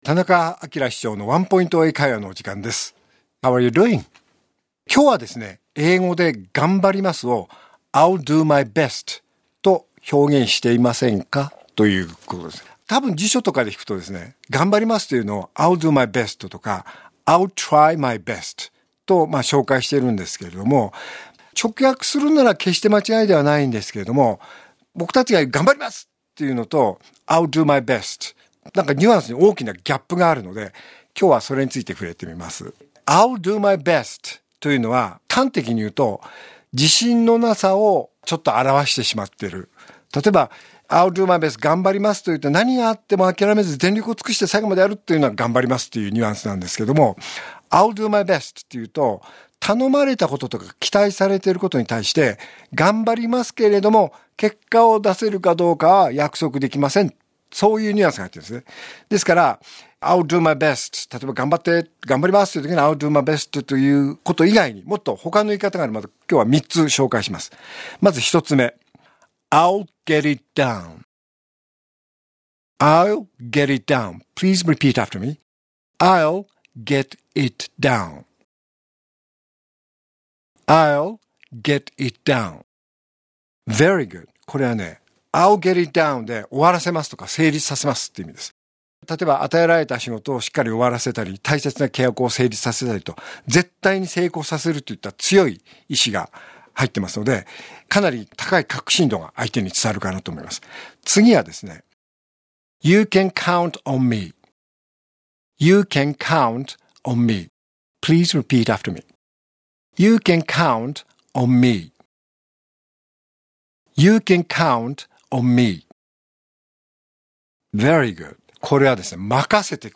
R5.9 AKILA市長のワンポイント英会話